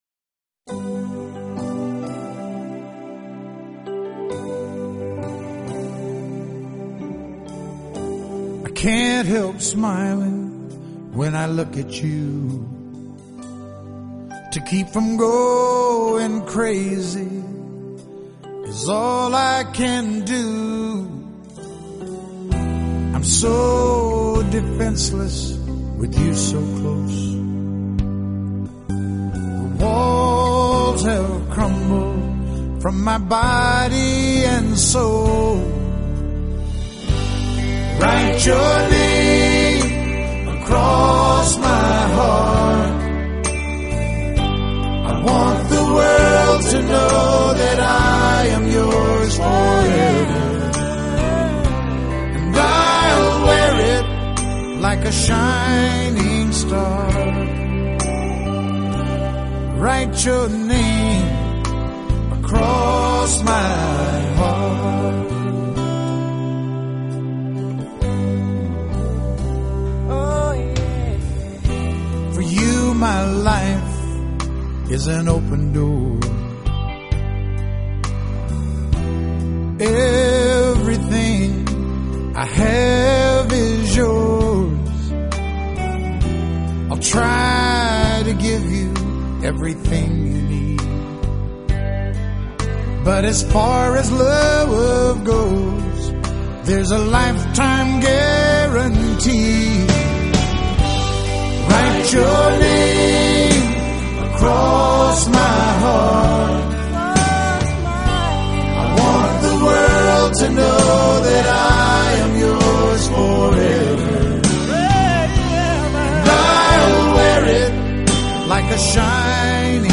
【乡村歌曲】
浪漫动人，加上发烧录音，令人一听难忘、百听不厌。